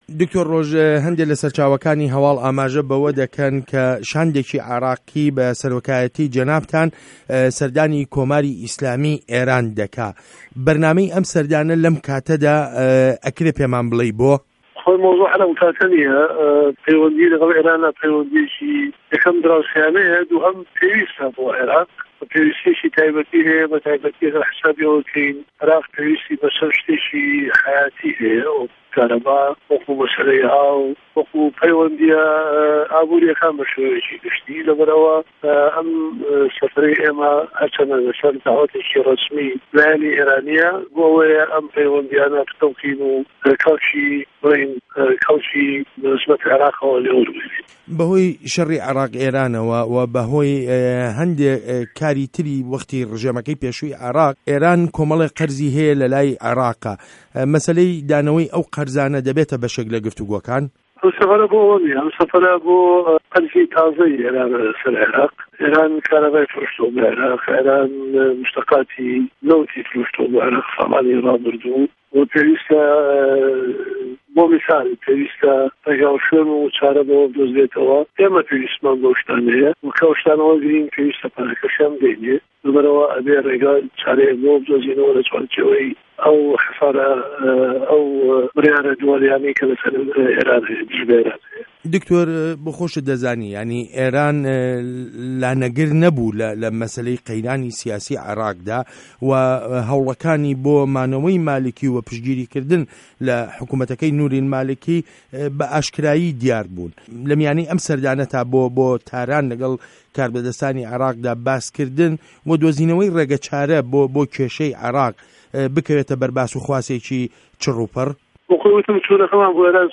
وتووێژ له‌گه‌ڵ دکتۆر ڕۆژ شاوێس